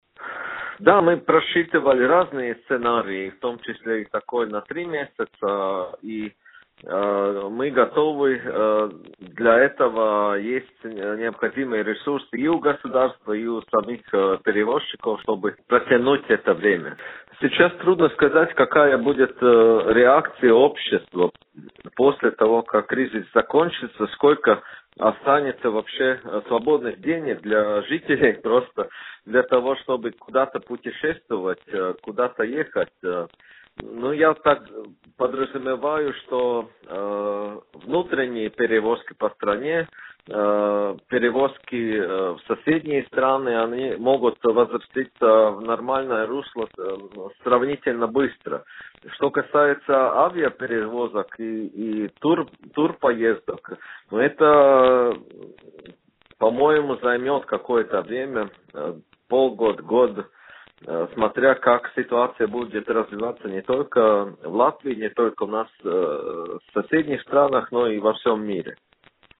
Предприятия, связанные с пассажирскими перевозками, готовы пережить еще три возможных месяца чрезвычайной ситуации. Об этом в интервью радио Baltkom заявил министр сообщения Латвии Талис Линкайтс.